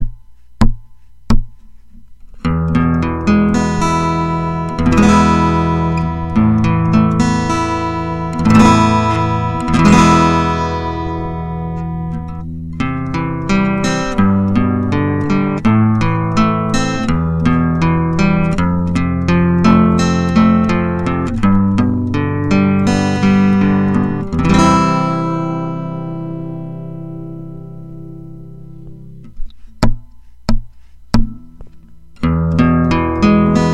・録音はピエゾ→自作のプリ→ミキサ→USBオーディオの変換器でライン入力→パソコン
今回はピエゾ２つなので、より低音を強調できる位置に変更。
全般にレベルがちょっと高かった・・・
ひずみ気味でごめんなさい。
piezo2_6gen_5_1gen_5.mp3